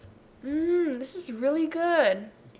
audio examples for Chapter 7: Expressing Positive Assessment
mmm-really-good.wav